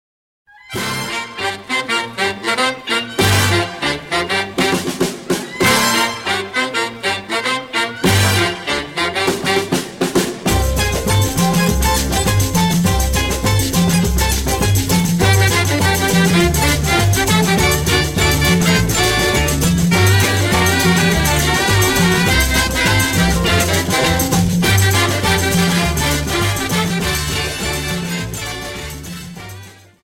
Samba 51 Song